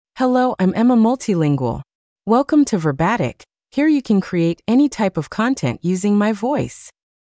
Emma MultilingualFemale English AI voice
Emma Multilingual is a female AI voice for English (United States).
Voice sample
Female
Emma Multilingual delivers clear pronunciation with authentic United States English intonation, making your content sound professionally produced.